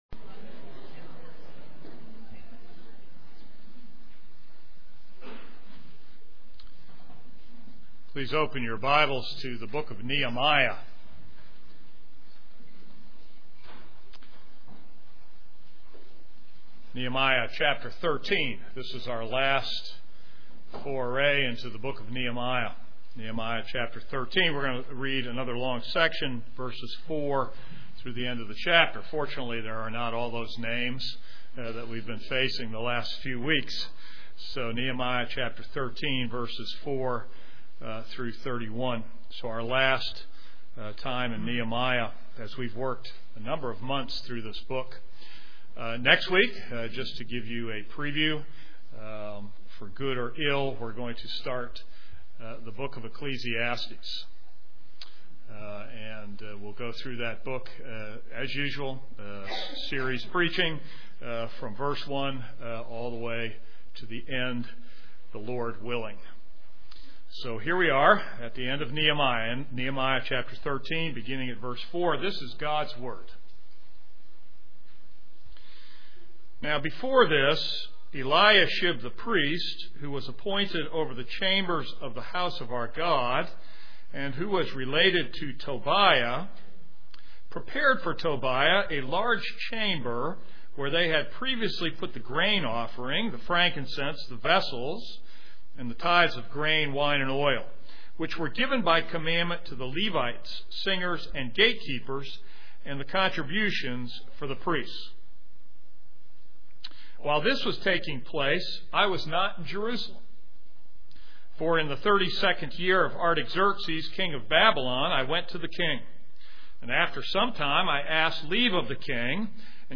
This is a sermon on Nehemiah 13:4-31.